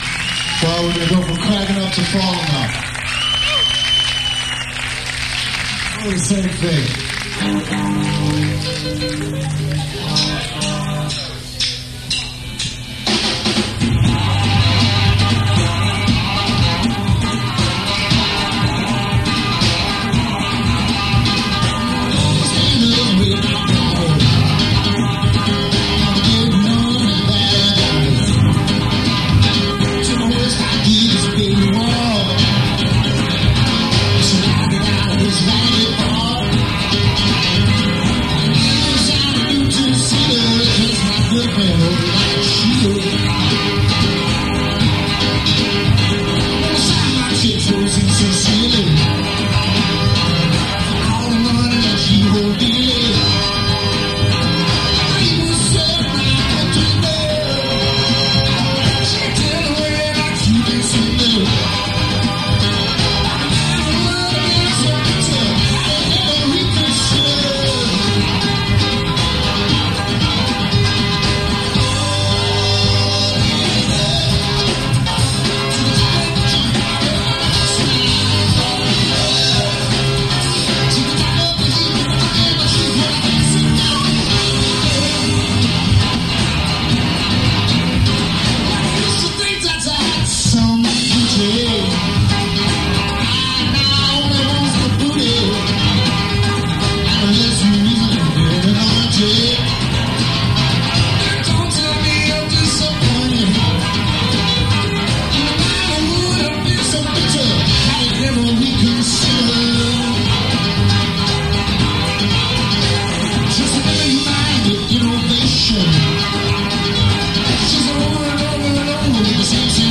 Attn: muddy and tinny sound